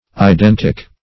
Identic \I*den"tic\, a.